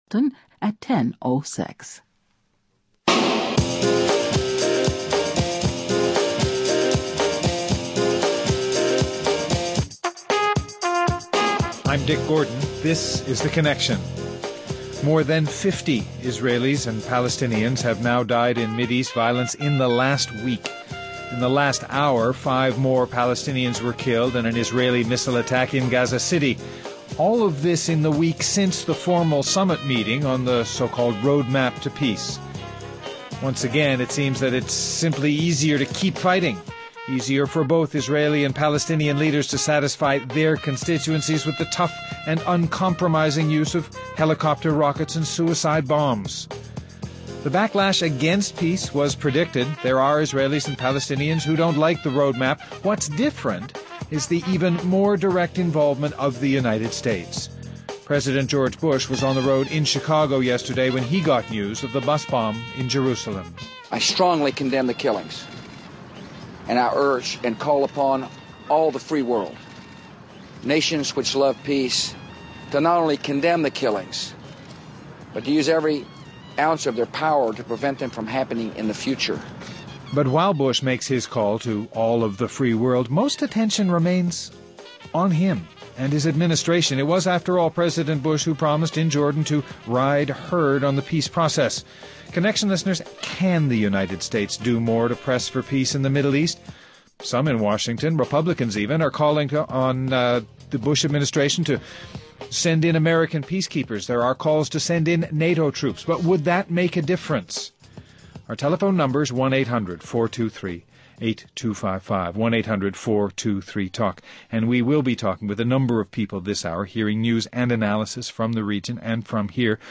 Guests: Richard Murphy, former Ambassador to Egypt and Syria Robin Wright, senior diplomatic correspondent, Los Angeles Times TBA.